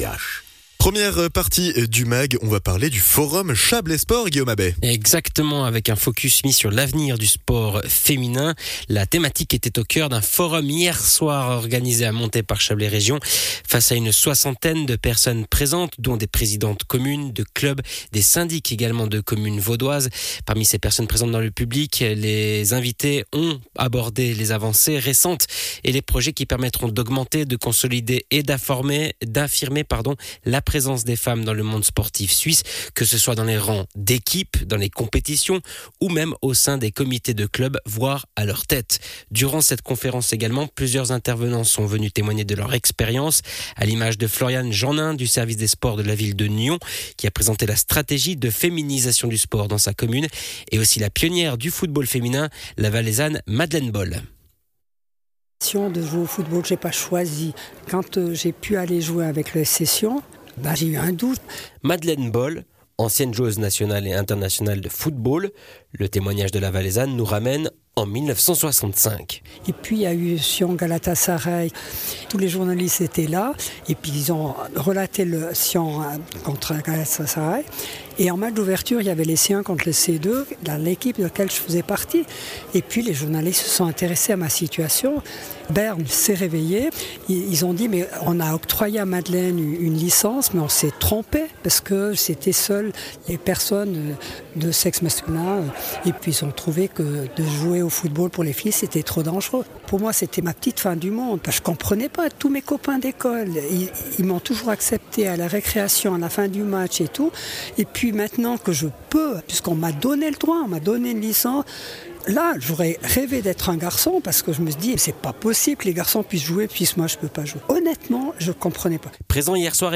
Intervenant(e) : Multi-intervenants